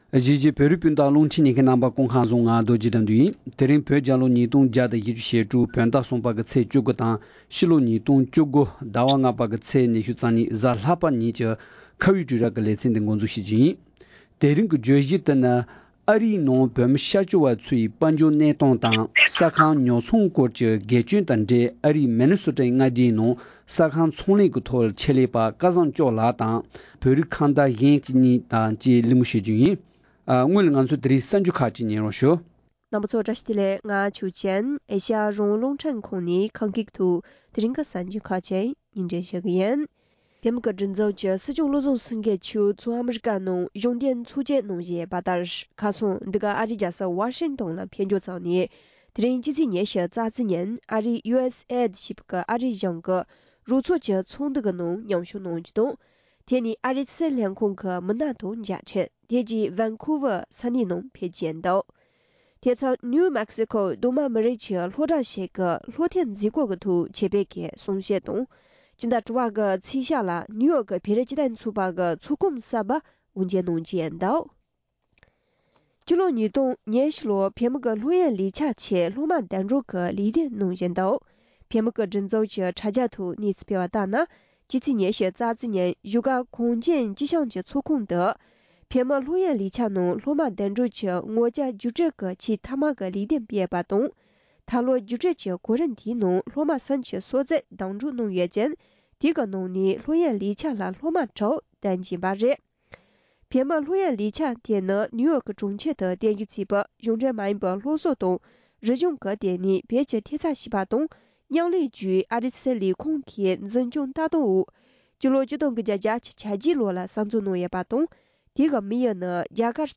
ཨ་རིའི་ནང་བོད་མི་སྐྱབས་བཅོལ་བ་ཚོའི་དཔལ་འབྱོར་གནས་སྟངས་དང་ས་ཁང་ཉོ་ཚོང་སྐོར་གྱི་དགེ་སྐྱོན་དང་འབྲེལ་བའི་ཐད་གླེང་མོལ་གནང་བ།